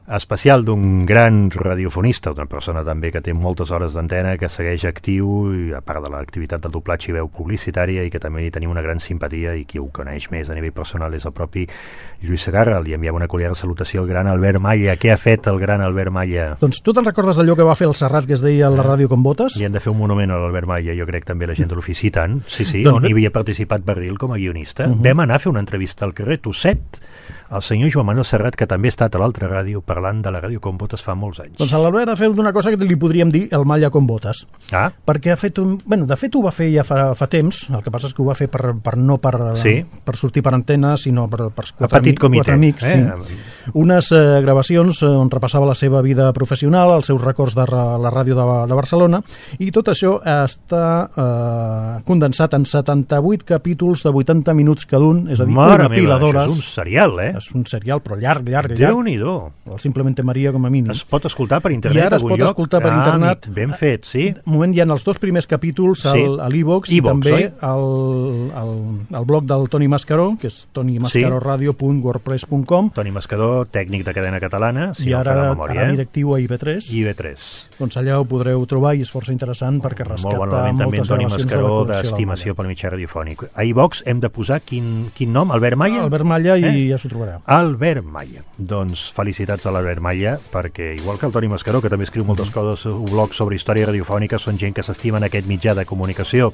Divulgació
Presentador/a